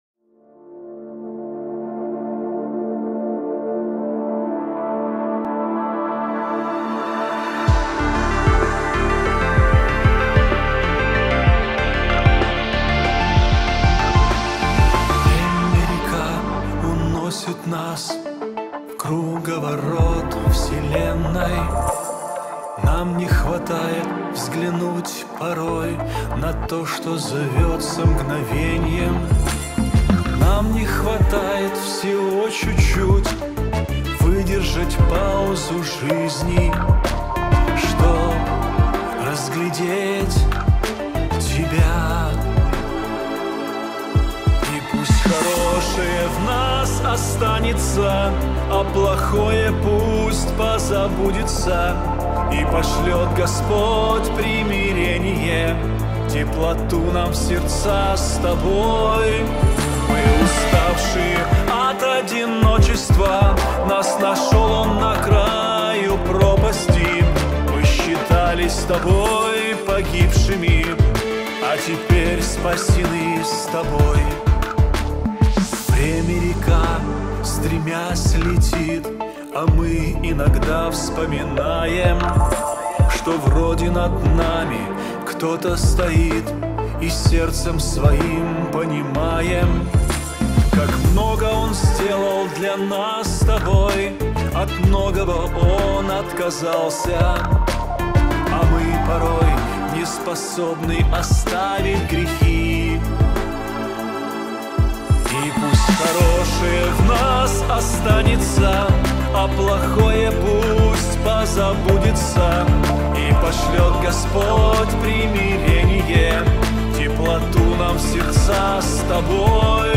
песня
232 просмотра 104 прослушивания 13 скачиваний BPM: 75